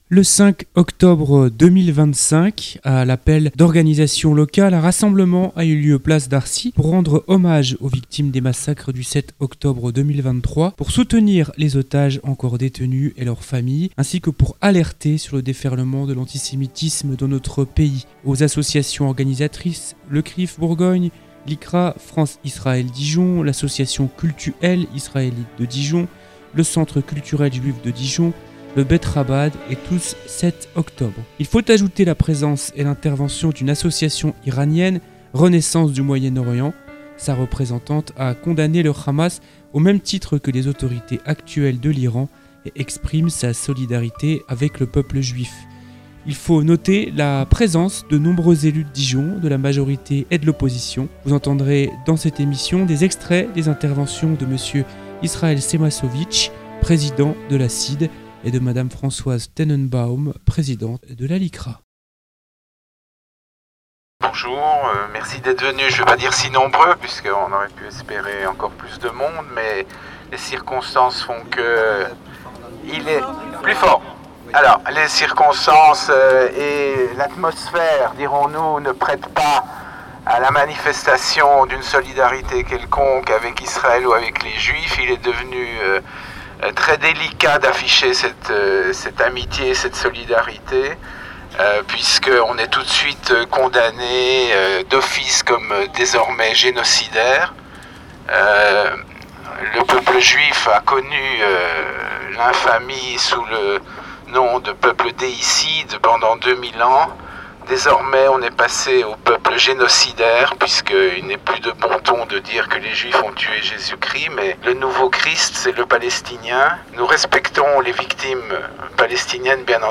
Combattre la haine Le 5 octobre 2025, à l'appel d'organisations locales, un rassemblement a eu lieu Place Darcy pour rendre hommage aux victimes des massacres du 7 octobre 2023, pour soutenir les otages encore détenus et leurs familles et pour alerter sur le déferlement de l'antisémitisme dans notre pays.
Vous entendrez dans cette émission des extraits des interventions